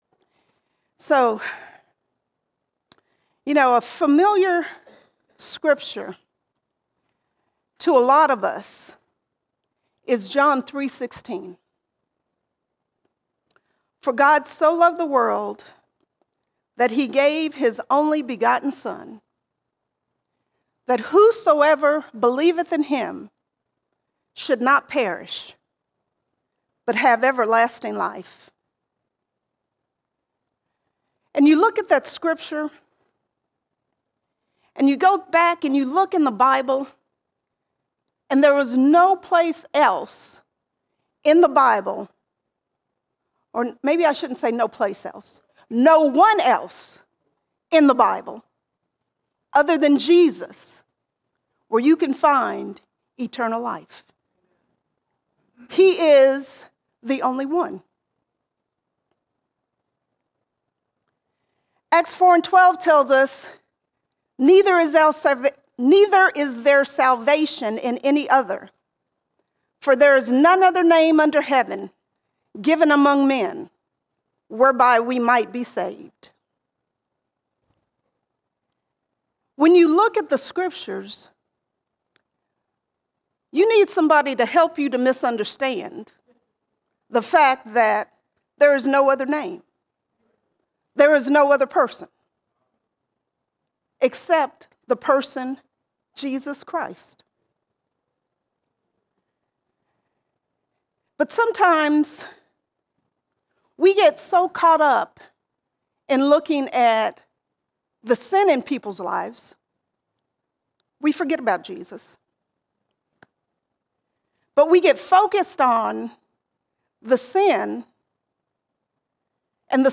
8 January 2024 Series: Sunday Sermons Topic: Jesus All Sermons The Only One The Only One Jesus is the One that God sent to saved the world.